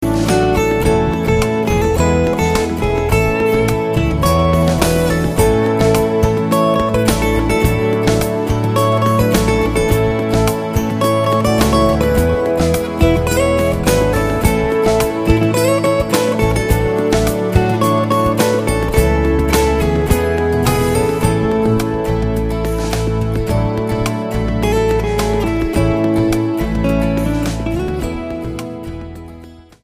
STYLE: Ambient/Meditational